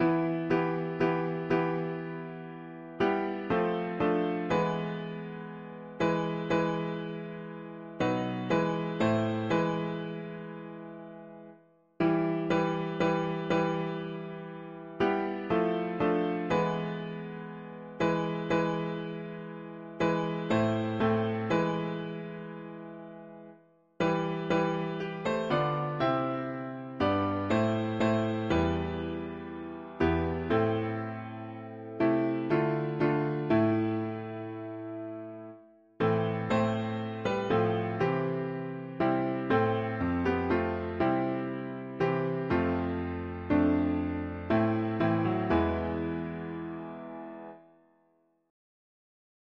If yet, while pardon may be f… english christian 4part chords
Key: E phrygian Meter: CMD